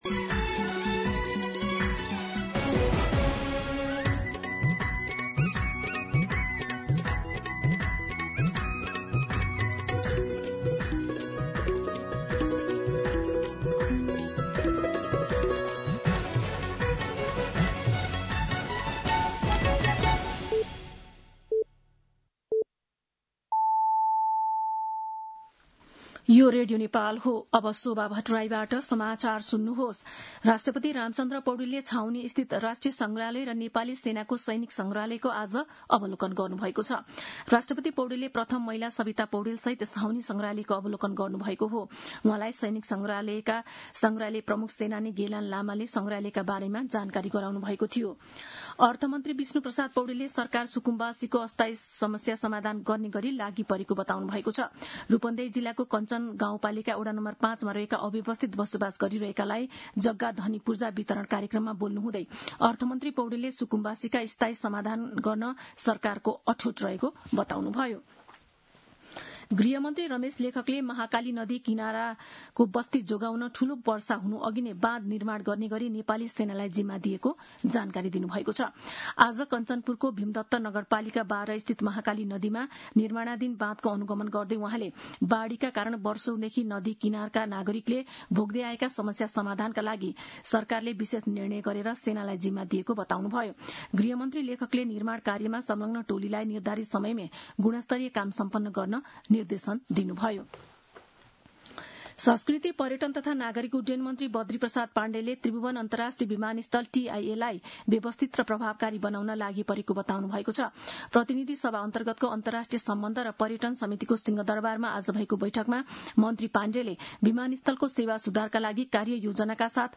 दिउँसो ४ बजेको नेपाली समाचार : २२ असार , २०८२